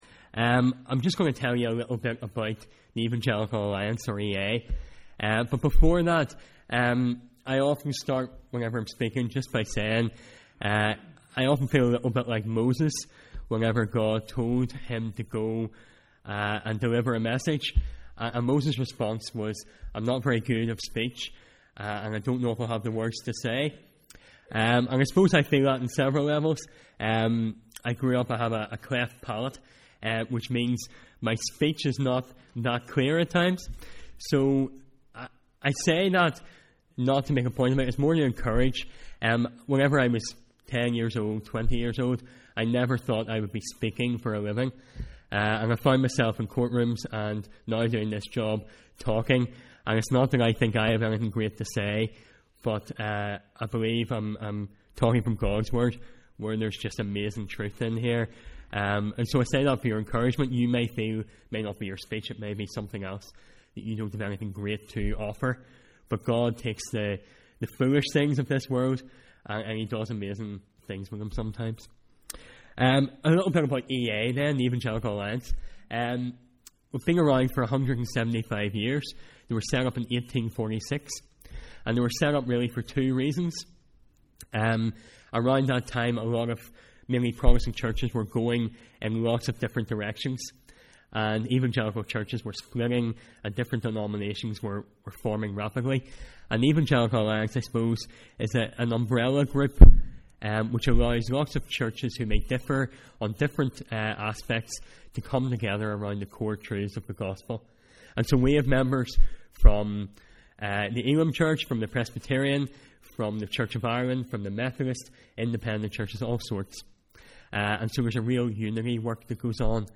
Evening Service: Sunday 24th February